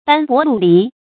注音：ㄅㄢ ㄅㄛˊ ㄌㄨˋ ㄌㄧˊ
斑駁陸離的讀法